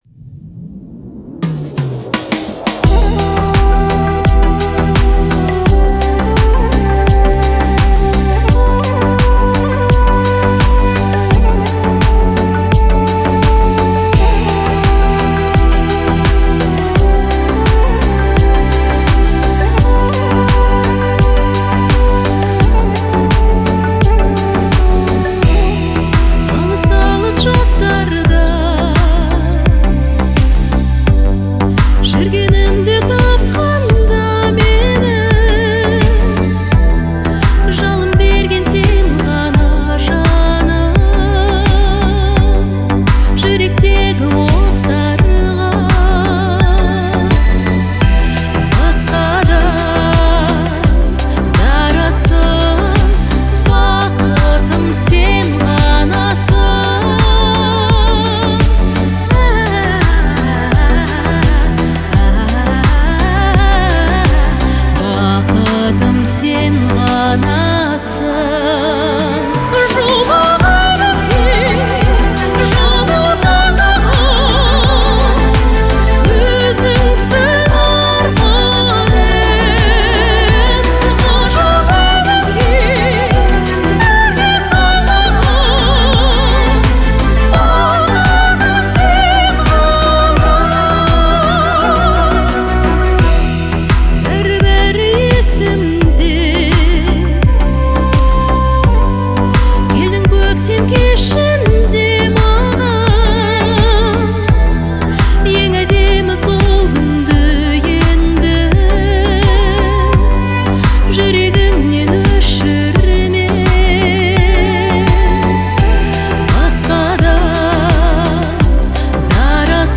Звучание песни отличается мелодичностью и гармонией